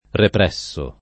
represso [ repr $SS o ]